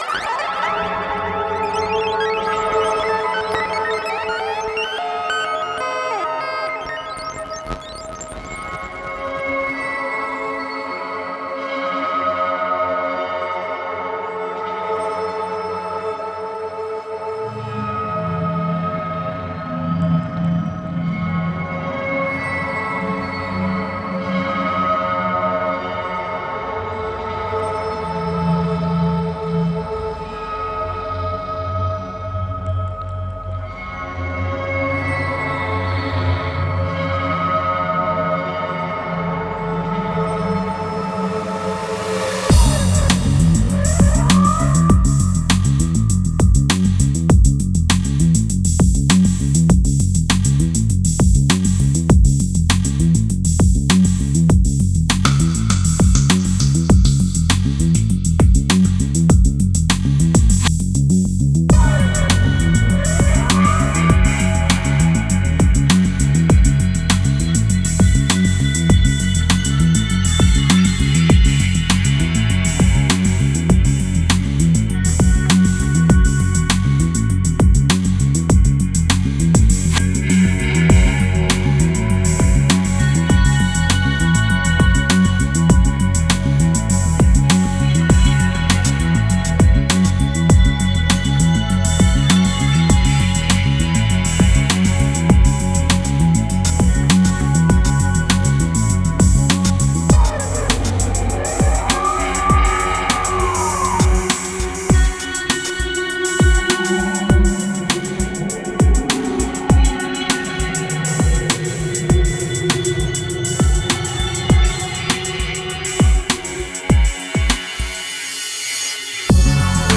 Genre: Psychill, Downtempo, IDM.